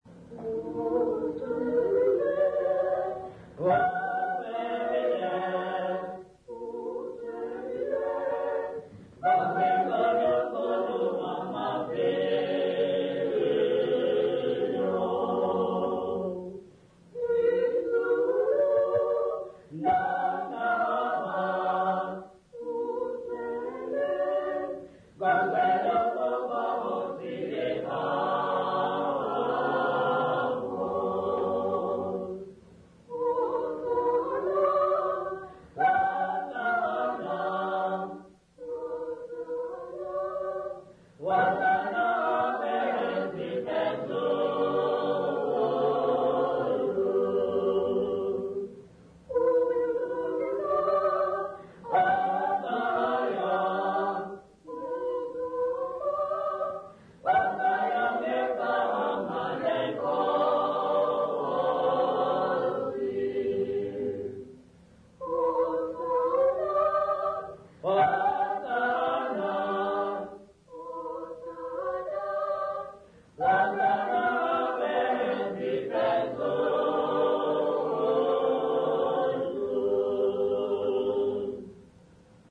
Intshanga church music workshop participants
Folk music South Africa
Hymns, Zulu South Africa
field recordings
Unaccompanied church hymn.